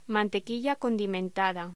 Locución: Mantequilla condimentada
voz